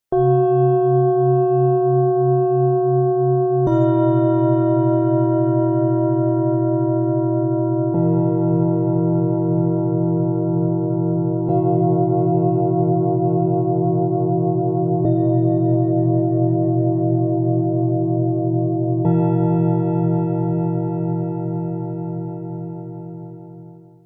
Freundliche Vitalität, tiefe Geborgenheit und Herzenswärme Klangmassage - Set aus 3 Planetenschalen, Ø 16,3 -21 cm, 2,46 kg
Tiefster Ton: Biorhythmus Körper – Sanfte Vitalität
Mittlerer Ton: Mond – Tiefe emotionale Berührung
Höchster Ton: Hopi Herzton – Freundliche Herzlichkeit
Der Hopi Herzton zeichnet sich durch seine harmonischen, freundlichen Schwingungen aus, die eine liebevolle Verbindung fördern.
Bengalen Schale, Matt, 21 cm Durchmesser, 9 cm Höhe